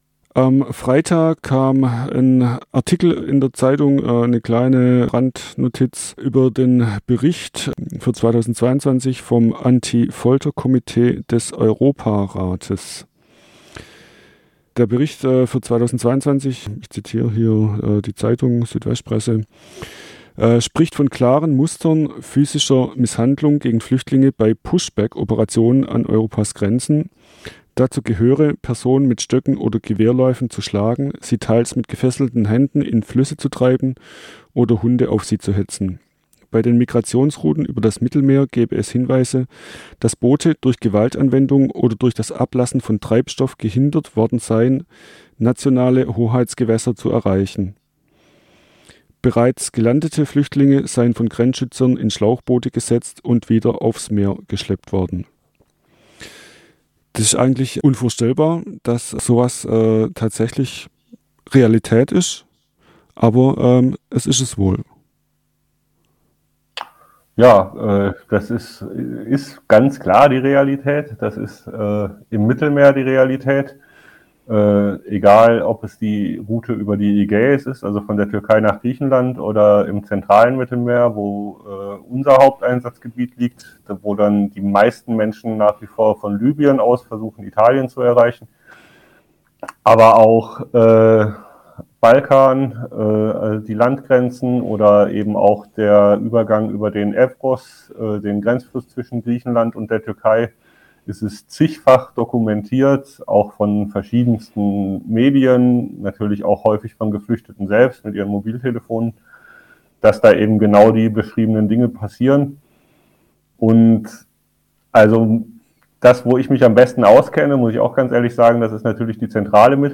Interview Teil 3